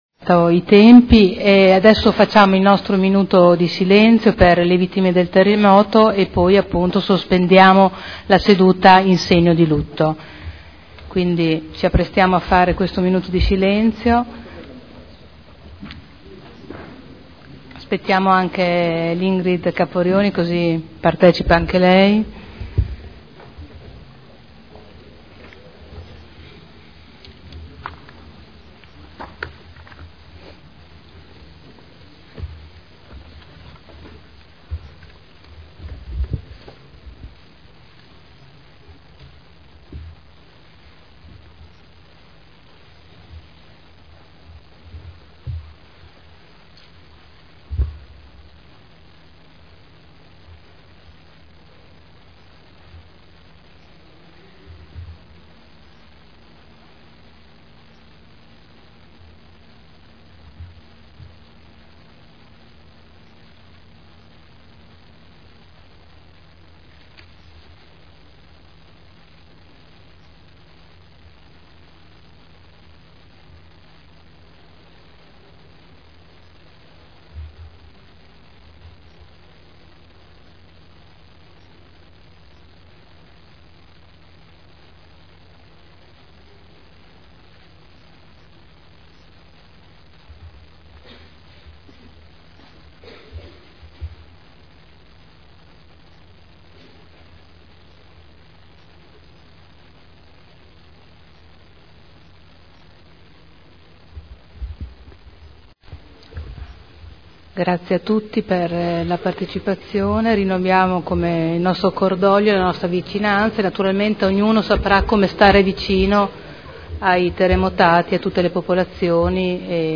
Seduta del 21 maggio Minuto di silenzio come espressione di cordoglio per le vittime del terremoto e sospensione della seduta in segno di lutto